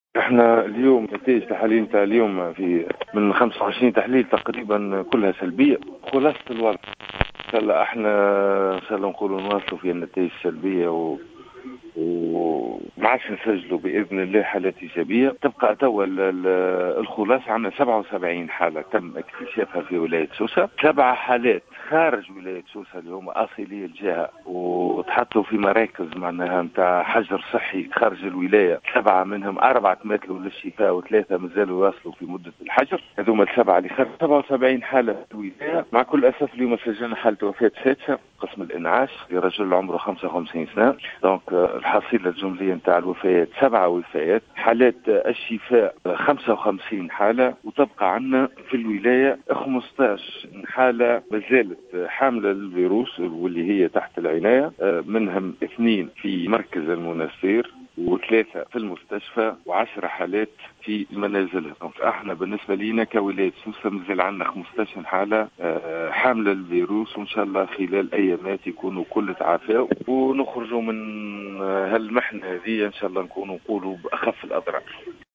أفاد المدير الجهوي للصحة بسوسة سامي الرقيق في تصريح لـ "الجوهرة اف أم" اليوم الخميس، أنه تم تسجيل حالة وفاة جديدة في سوسة لمصاب بفيروس كورونا يقيم بمستشفى فرحات حشاد، ليرتفع بذلك عدد الوفيات الجملي بالجهة، إلى 7 حالات.